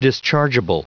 Prononciation du mot dischargeable en anglais (fichier audio)
Prononciation du mot : dischargeable